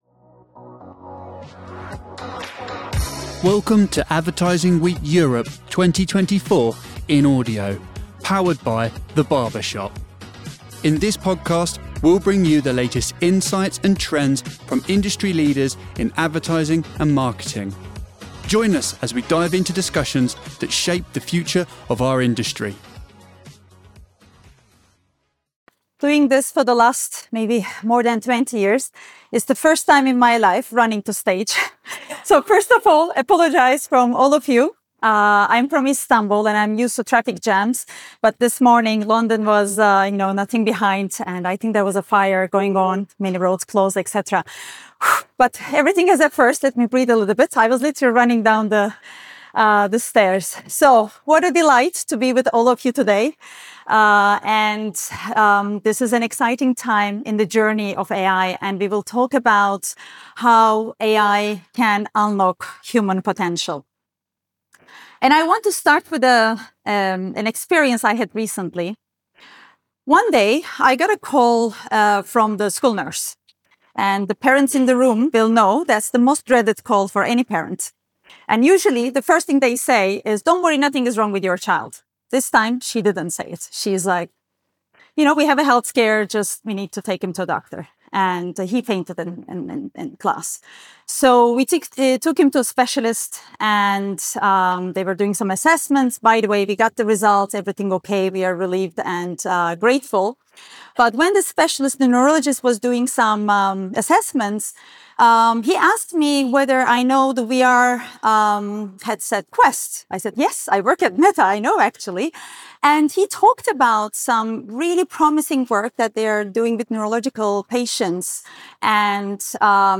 This talk explores how AI is revolutionizing creative development, messaging, and mixed reality experiences.